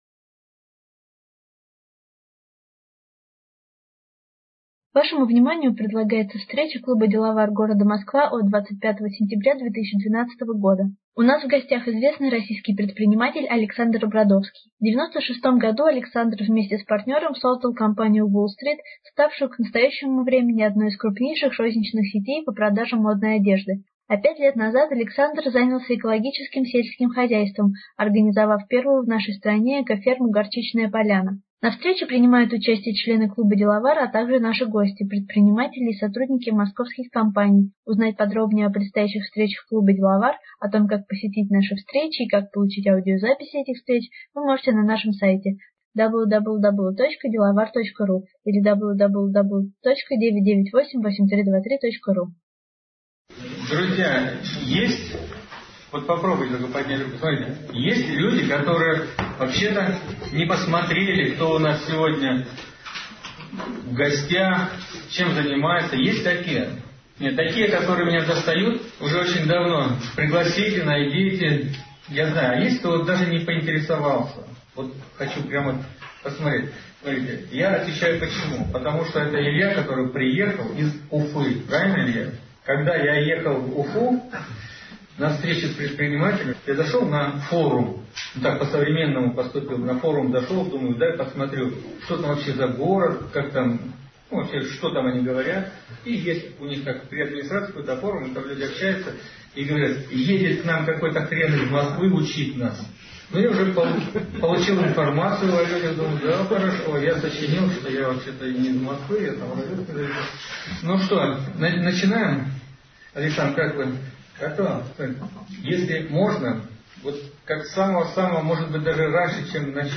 25 сентября 2012 года московский клуб предпринимателей "Деловар" провел очередную встречу.